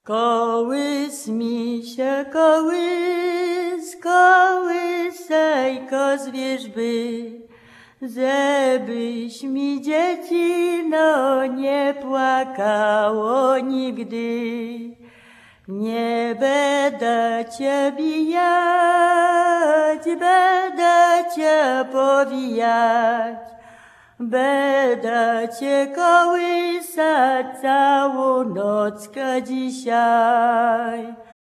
KOŁYSANKI LULLABIES
The lullabies, children's songs, counting-out games and children's play contained on this CD come from the Polish Radio collection.
Half of the recorded tracks are lullabies (tracks 1-30), mostly in recordings from the 1970s, 1980s and 1990s, performed by singers born in the early 20th century.[...]